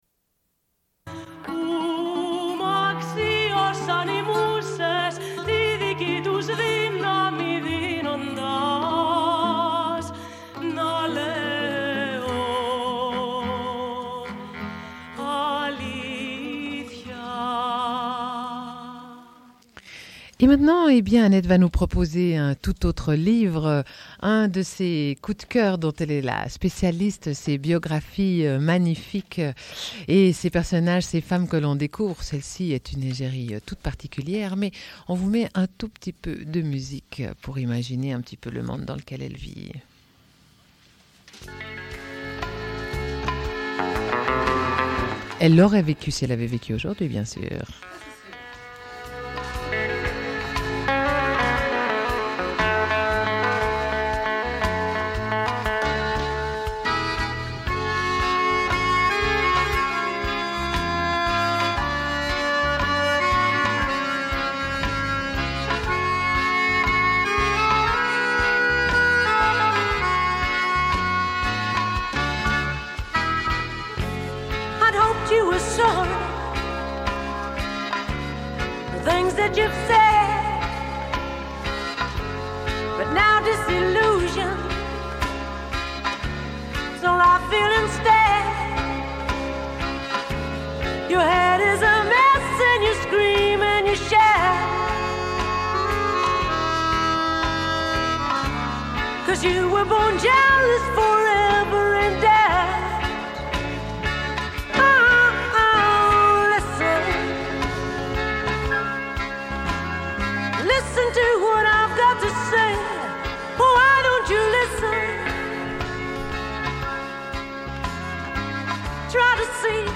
Une cassette audio, face B29:28